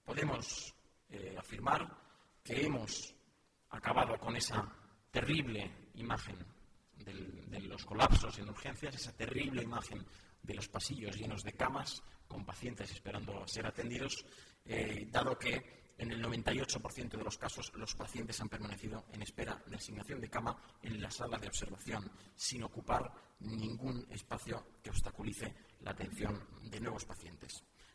portavoz_gobierno_-dignifica.mp3